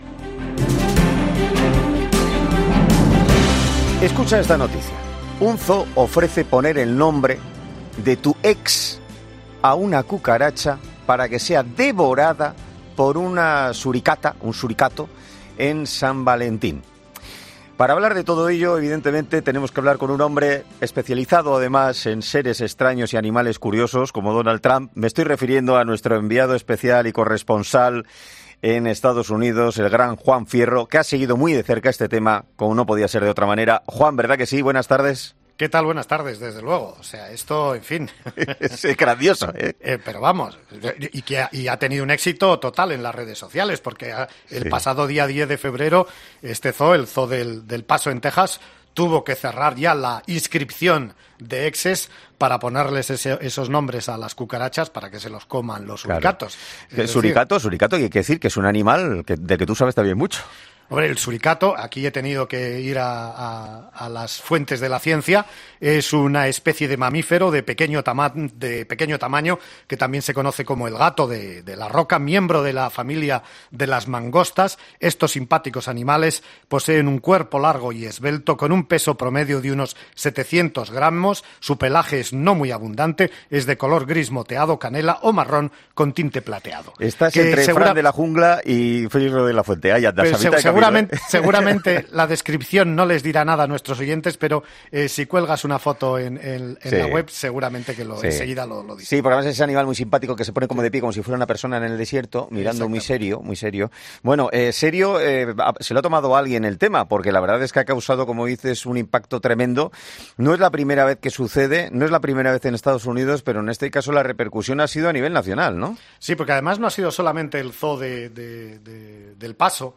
Para conocer todos los detalles, este jueves ha sido entrevistado en 'Herrera en COPE'